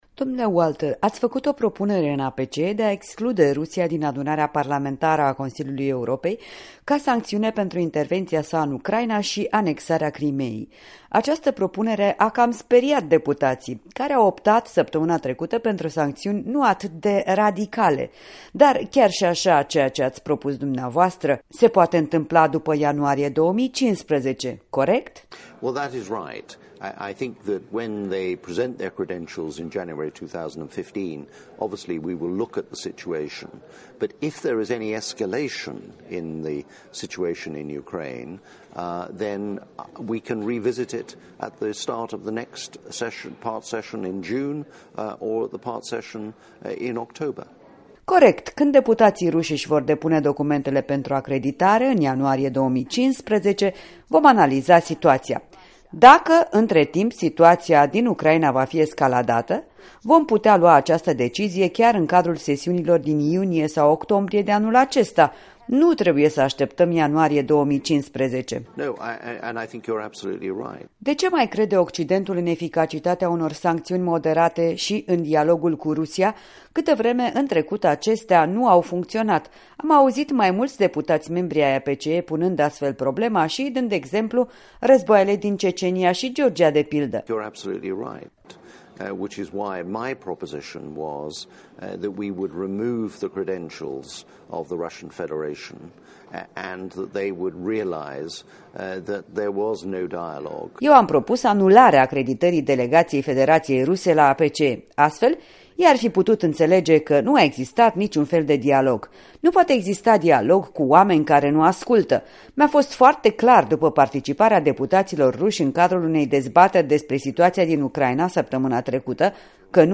În direct de la Strasbourg: în dialog cu europarlamentarul britanic Robert Walter